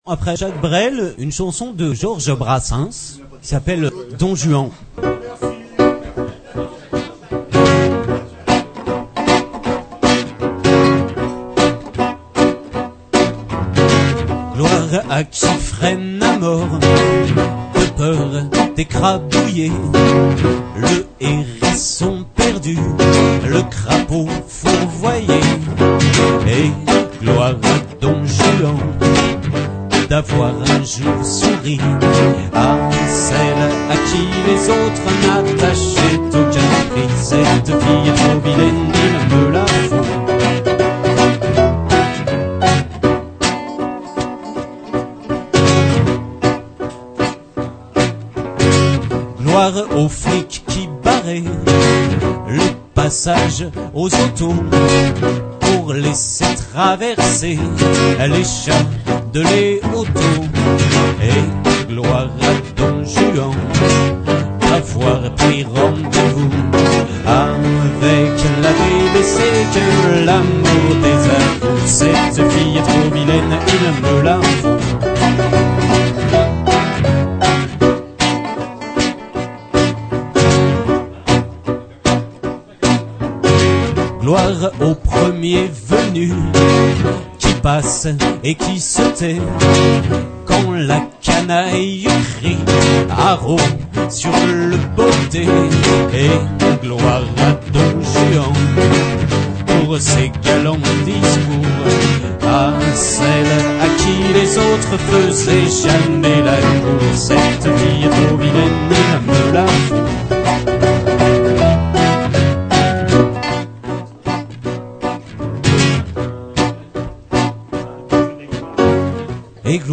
Bm Allegro
live